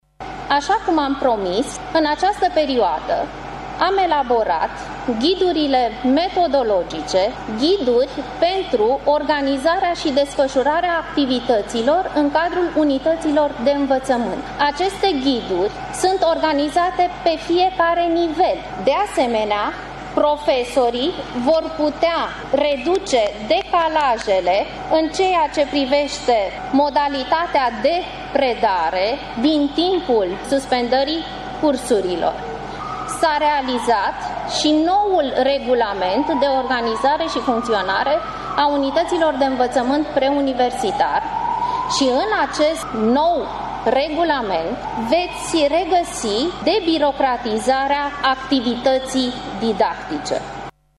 Ministrul Educaţiei şi Cercetării, Monica Anisie, a declarat azi, într-o conferinţă de presă, că au fost elaborate ghidurile metodologice pentru organizarea şi desfăşurarea activităţilor în cadrul unităţilor de învăţământ.